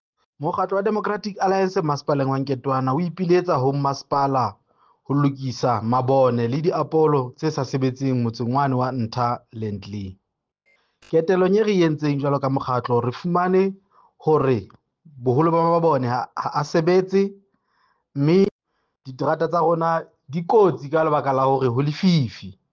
Sesotho soundbites by Cllr Diphapang Mofokeng.